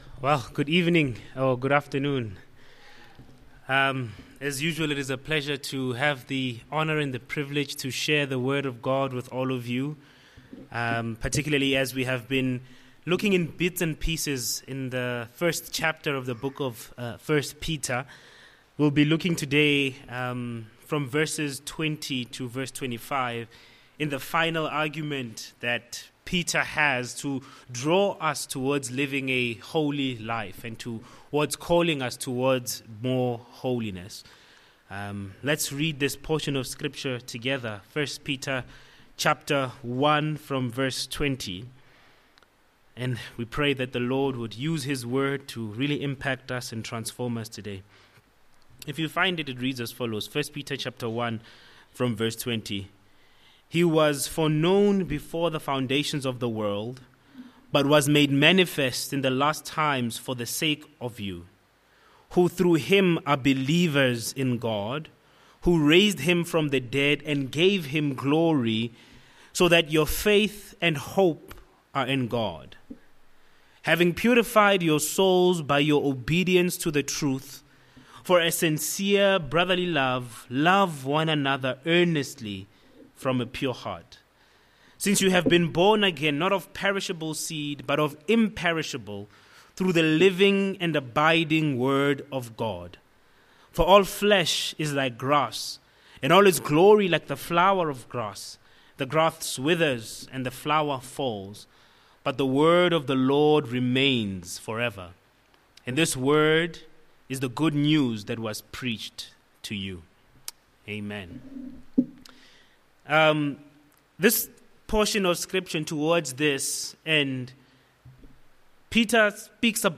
Sermons
Heritage Baptist Church Potchefstroom sermons